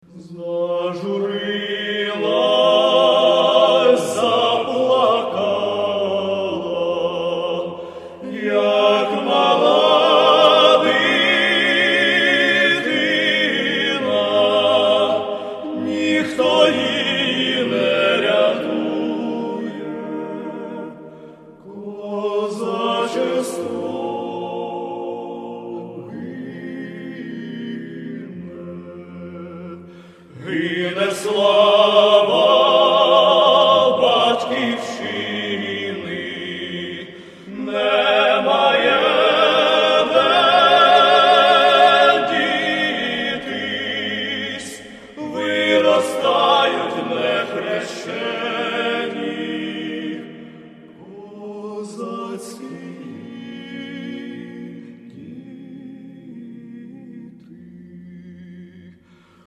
Musik- und Bilderabend am Do. 06. Nov. um 19 Uhr im Hotel Ostertor